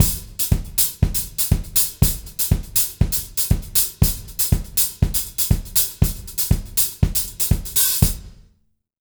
120ZOUK 03-R.wav